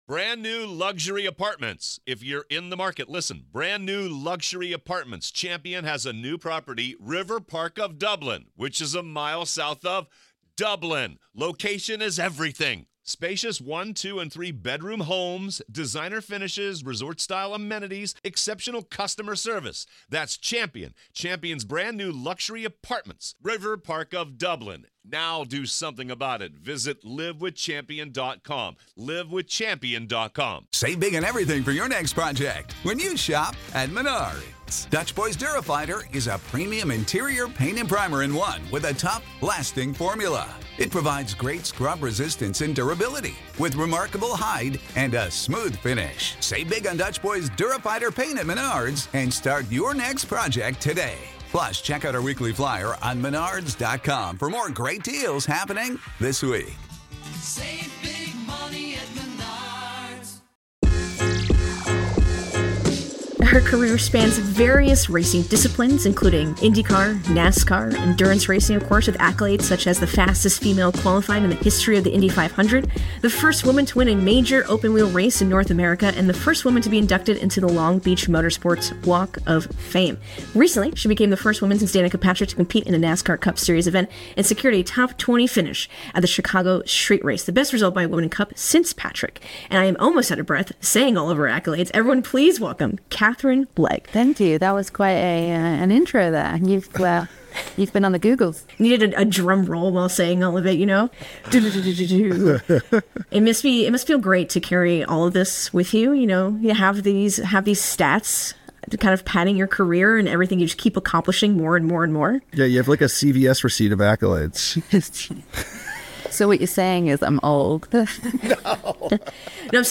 A Conversation with Racing Driver Katherine Legge